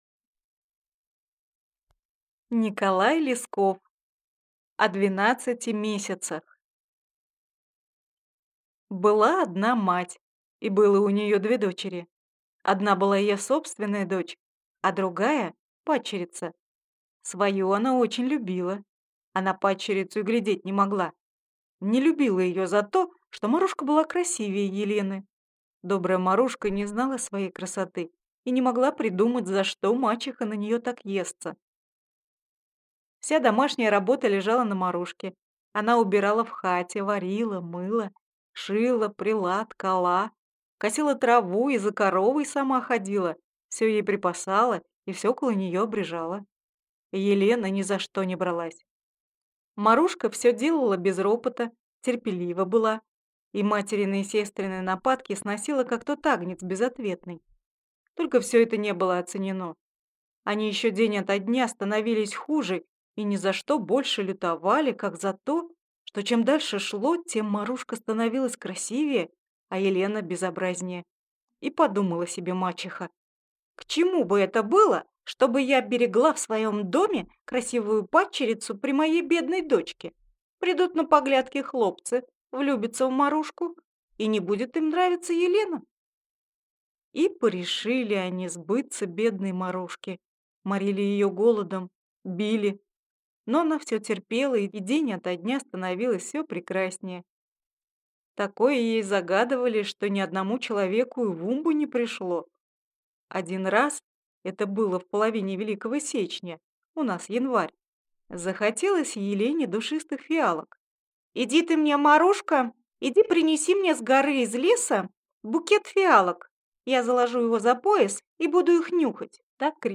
Аудиокнига О двенадцати месяцах | Библиотека аудиокниг